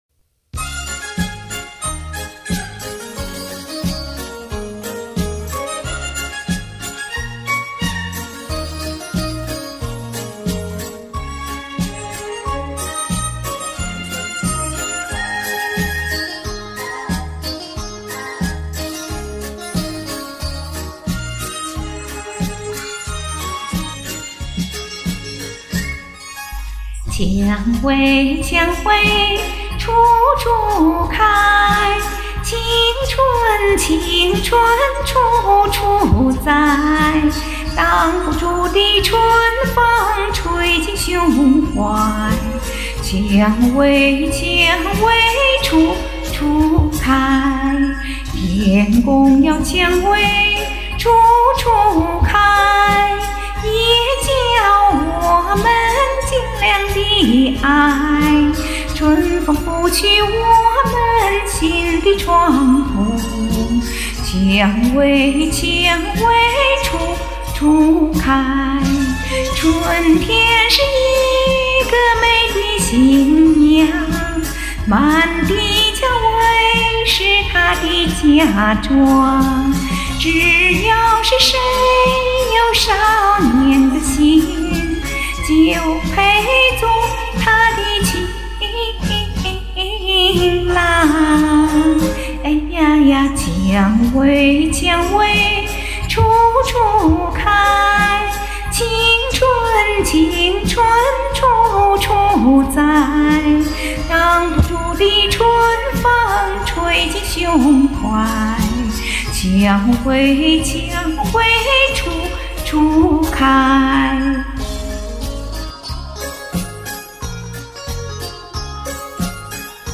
動聽的老歌！